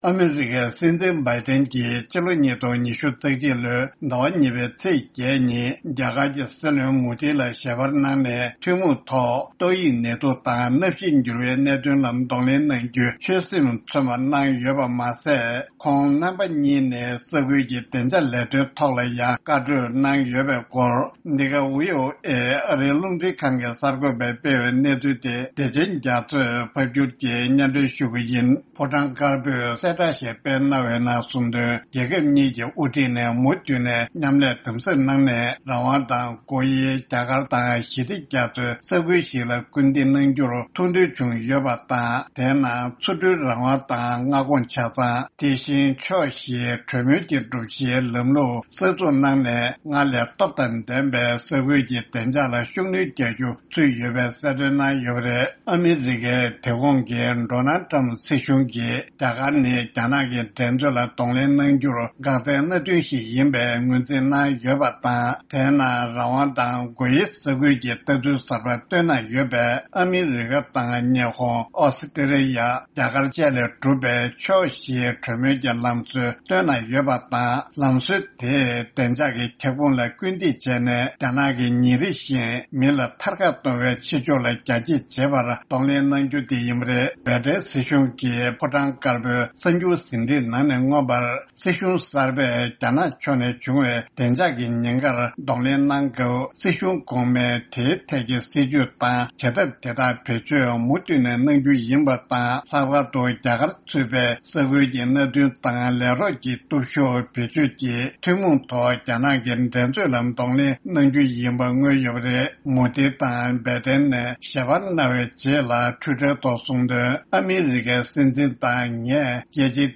ཕབ་སྒྱུར་དང་སྙན་སྒྲོན་ཞུ་རྒྱུ་རེད།།